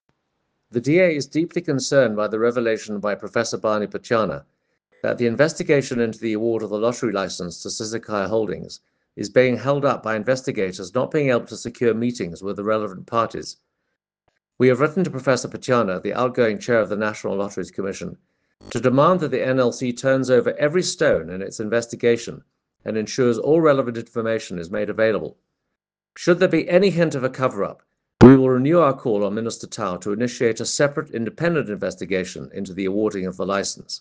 Soundbites by Toby Chance MP